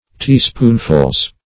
Teaspoonfuls - definition of Teaspoonfuls - synonyms, pronunciation, spelling from Free Dictionary
Teaspoonfuls (t[=e]"sp[=oo]n`f[.u]lz) or Teaspoonsful.